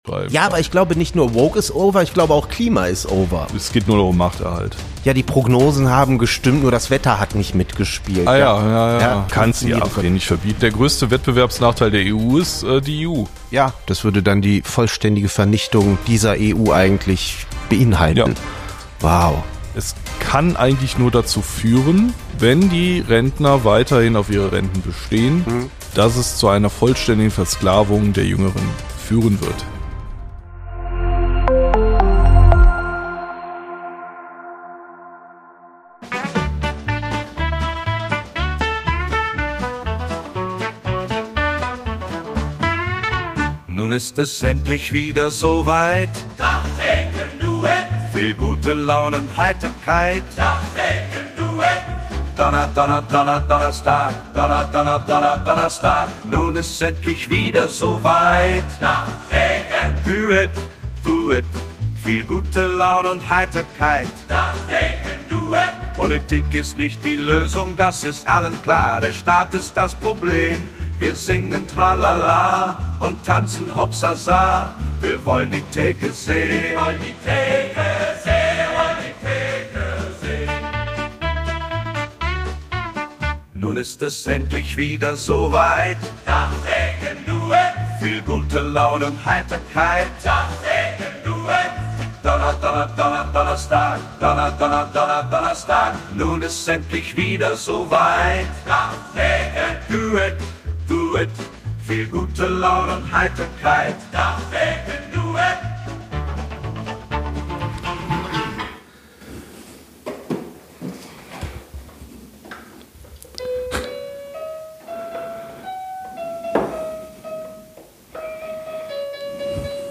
Kleine Redaktionskonferenz am Tresen